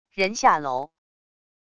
人下楼wav音频